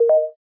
enter.aiff